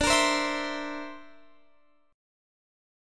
D7sus2#5 chord